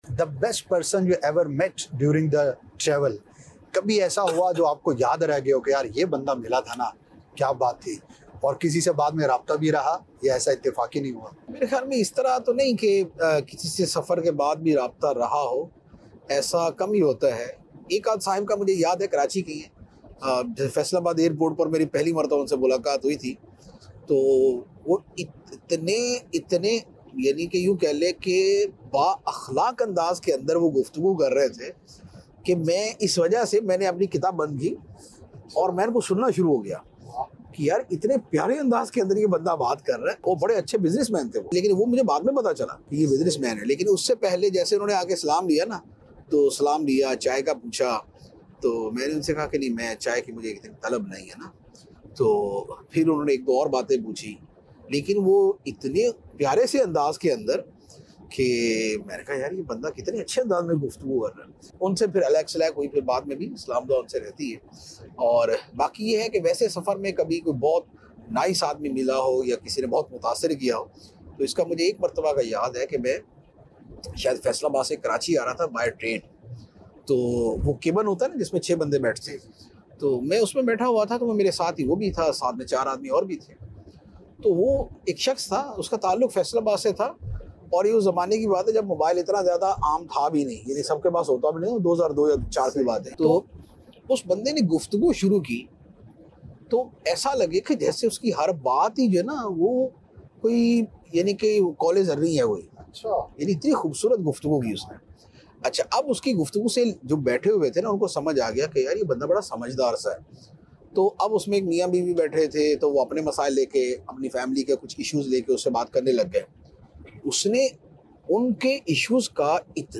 khutba